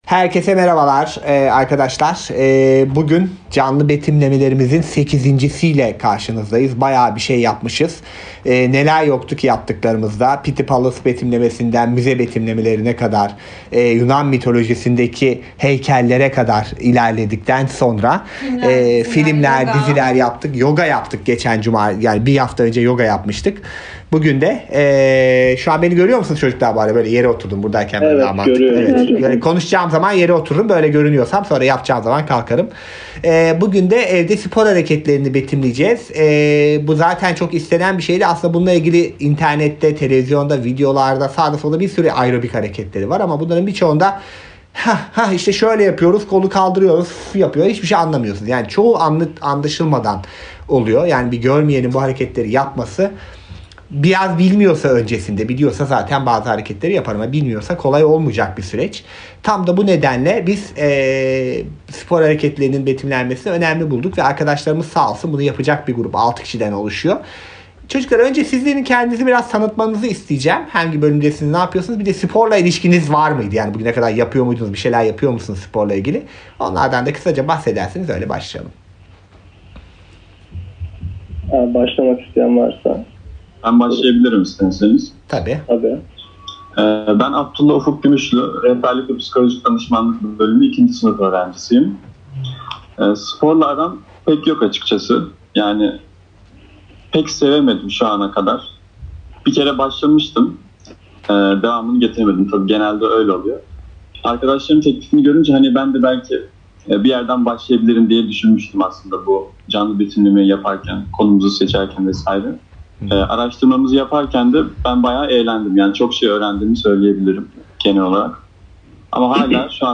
Canlı Betimlemeler